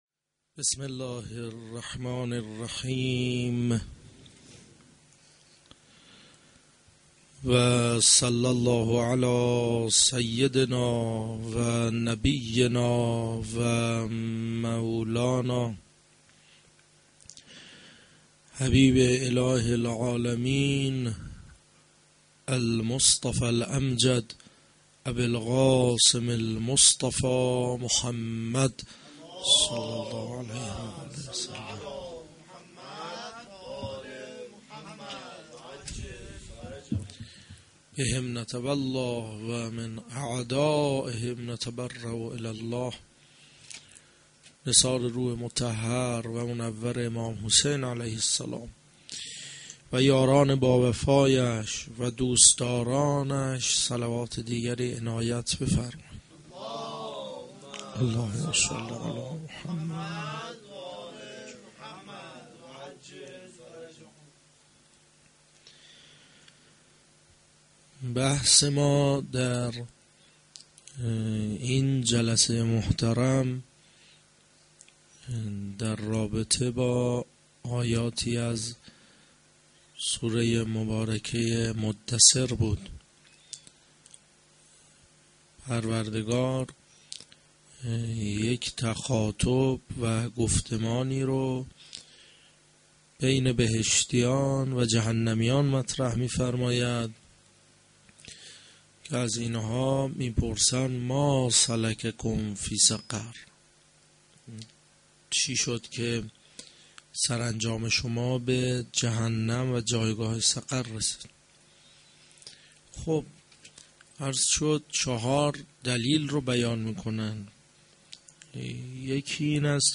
mo92-sh3-Sokhanrani.mp3